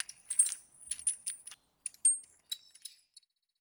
Foley Sports / Boxing / Chain Movement Normal.wav
Chain Movement Normal.wav